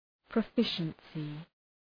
Προφορά
{prə’fıʃənsı}